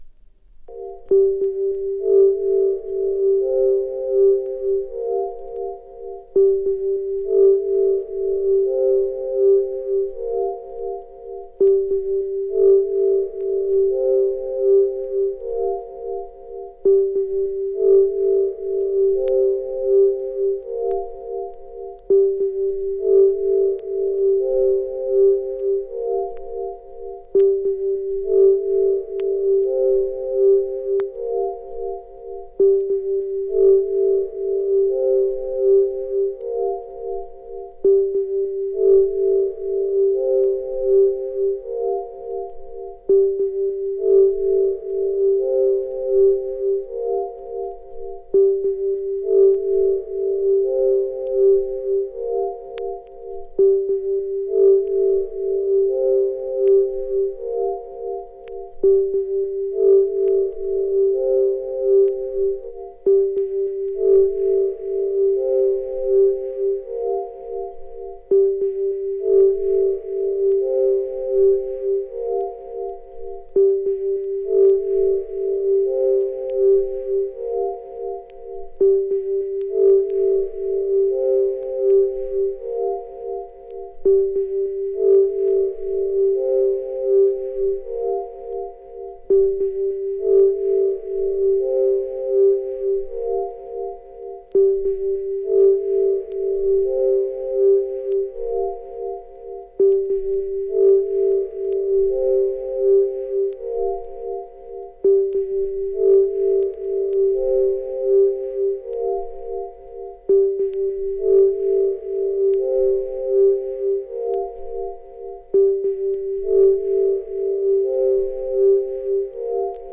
白ラベル仕様の限定LPで、淡くメランコリックなシンセ・ミニマリズムを収録。
太陽の光が差し込まない、どこか冷たく美しい北欧の風景を思わせるサウンド。
loner synth / contemplative ambient